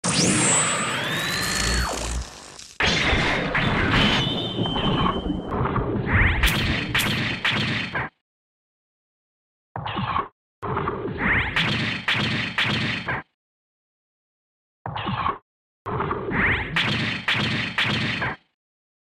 😲🇪🇬 Each Brawler's animation is played silent but a random voiceline. So, I added SFX to spice things up.